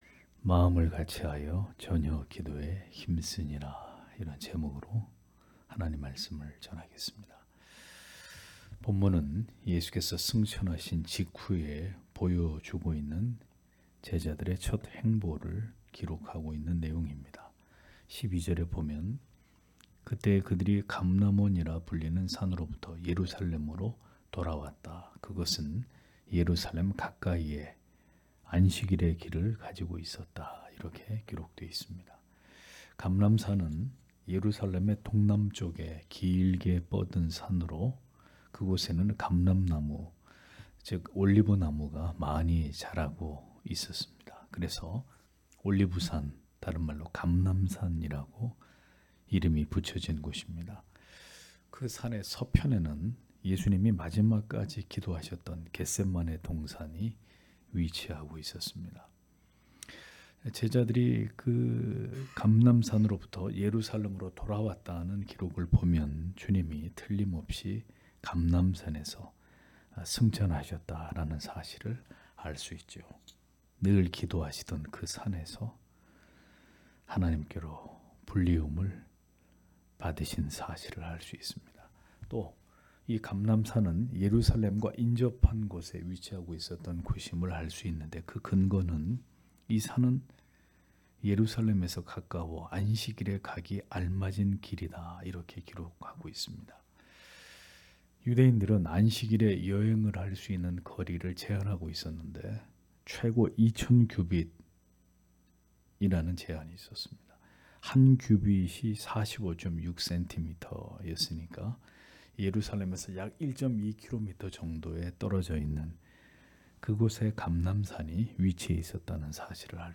금요기도회 - [사도행전 강해 05] 마음을 같이 하여 전혀 기도에 힘쓰니라 (행 1장 12- 14절)